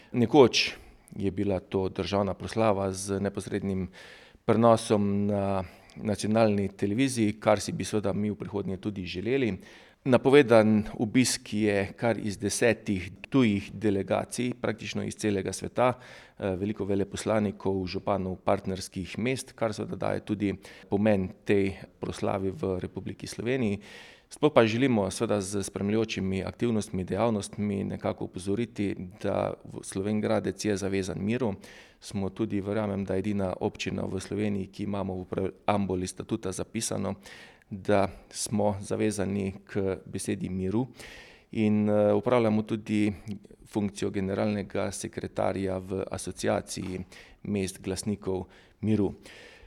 Župan Slovenj Gradca Tilen Klugler:
IZJAVA TILEN KLUGLER 1.mp3